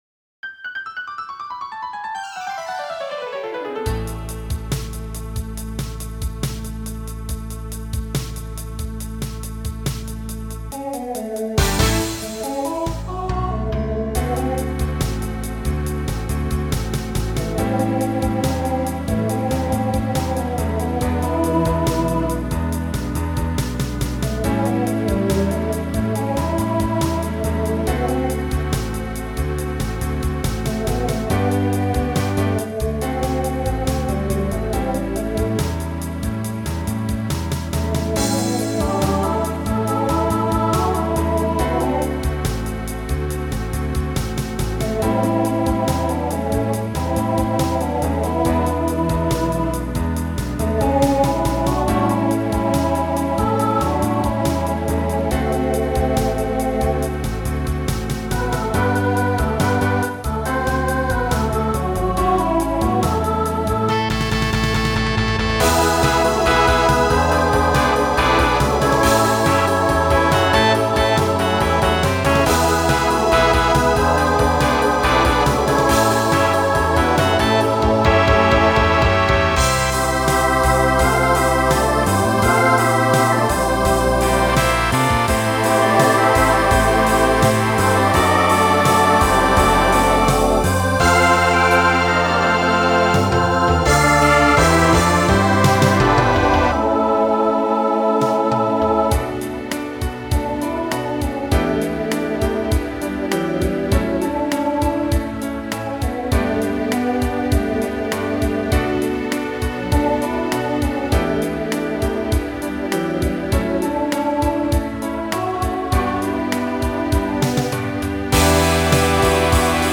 Voicing Mixed Instrumental combo Genre Pop/Dance , Rock